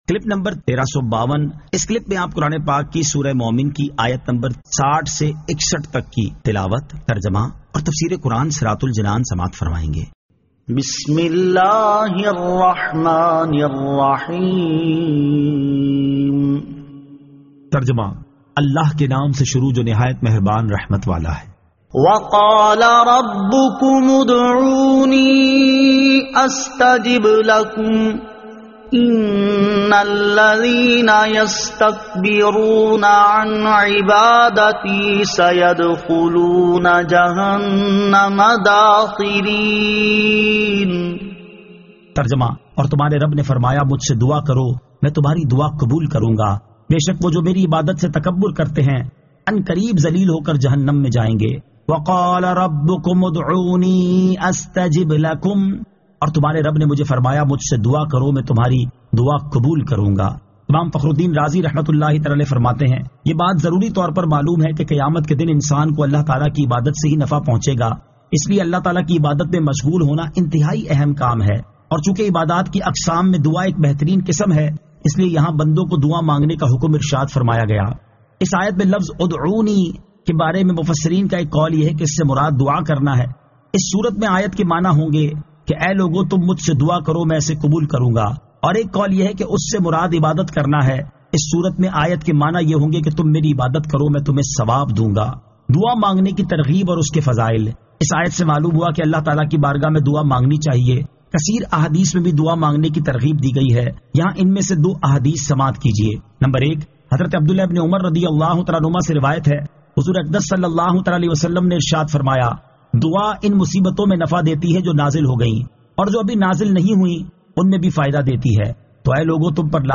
Surah Al-Mu'min 60 To 61 Tilawat , Tarjama , Tafseer